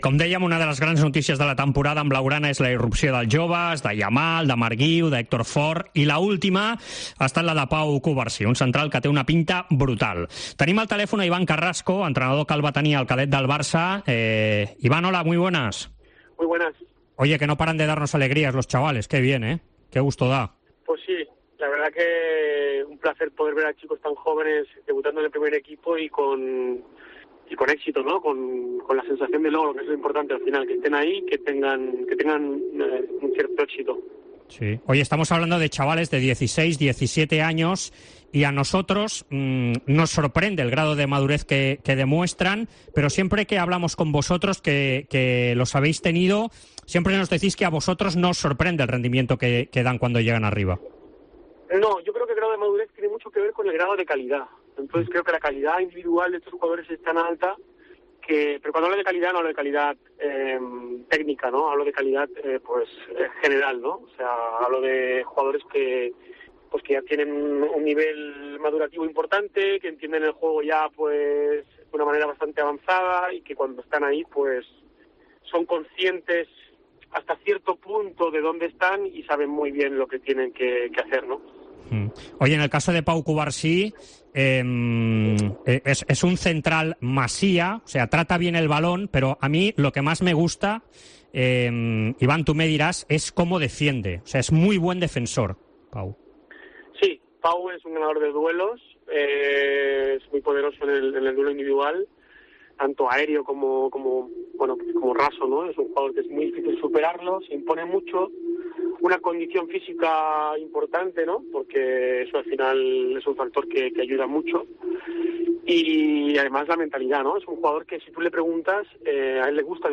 Entrevista Esports COPE